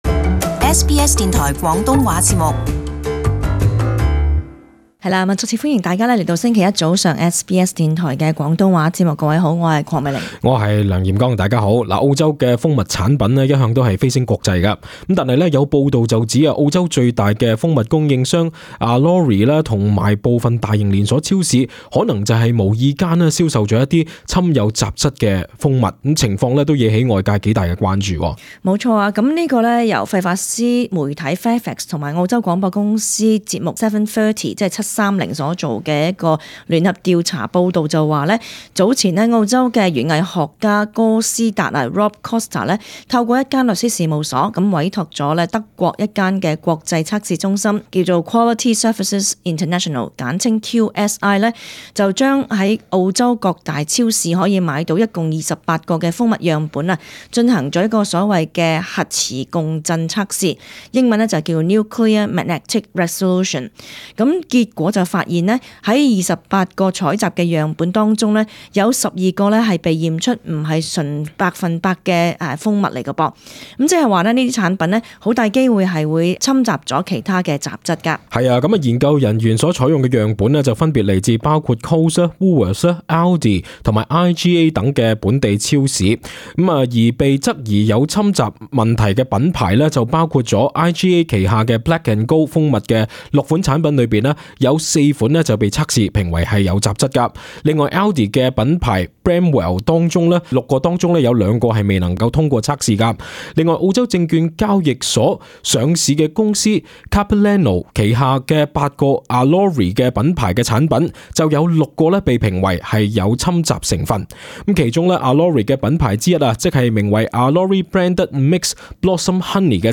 【時事報導】部份超市澳洲蜂蜜被指不純掀鑑定方法爭議